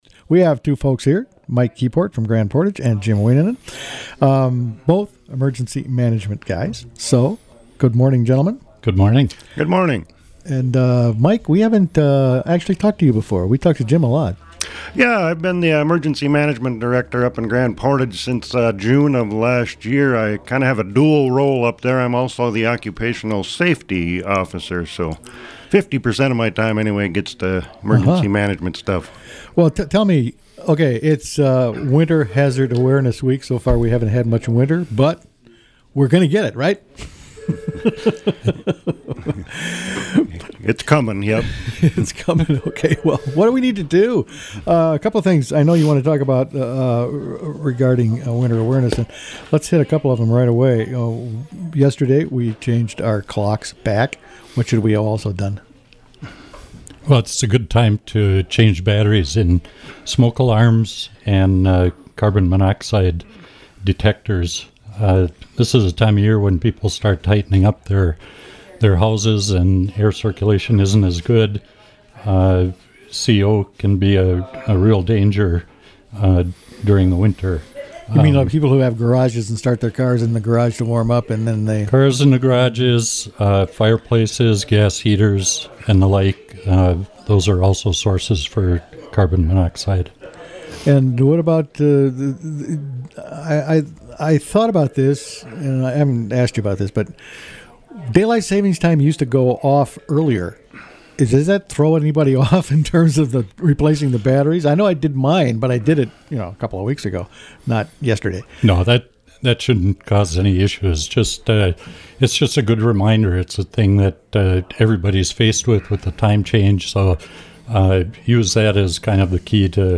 spoke with Emergency Managers